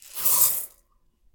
お米を鍋に入れ４
put_rice_in_pot4.mp3